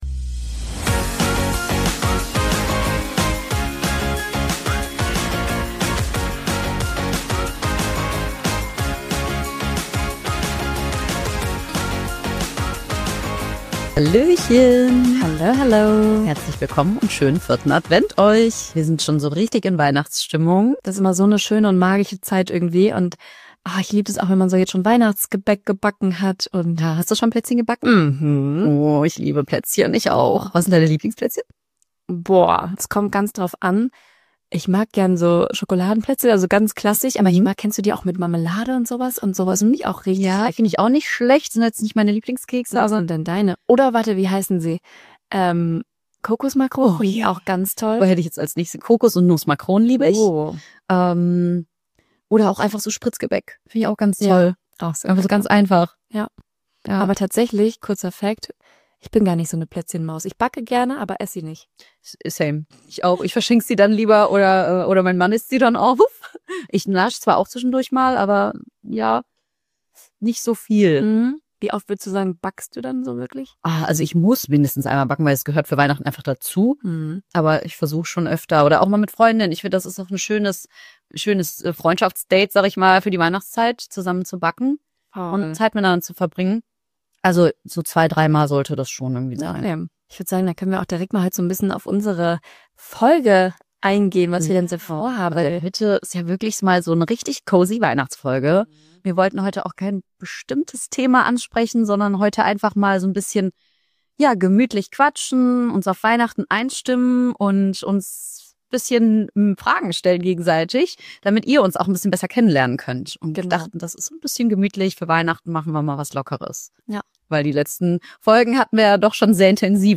Freut euch auf eine gemütliche Folge, wie ein Talk mit Freunden!